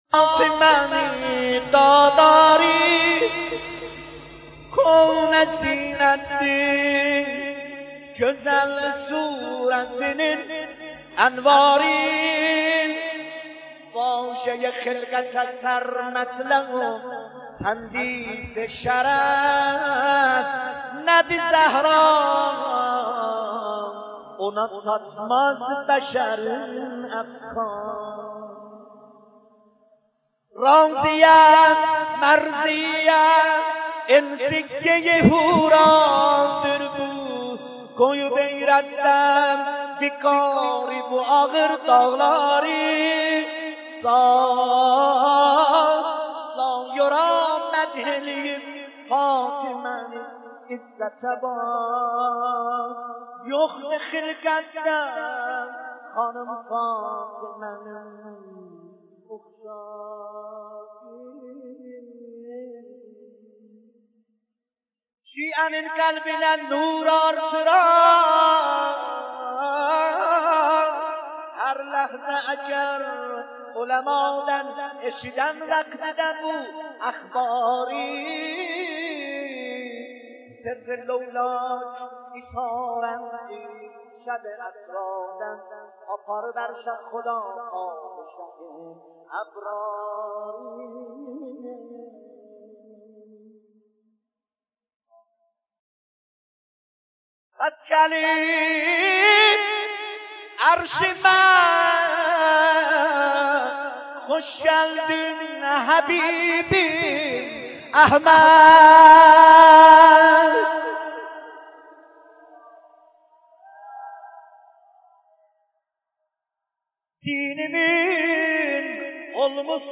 ذکر مصیبت شهادت مظلومانه حضرت زهرا(س)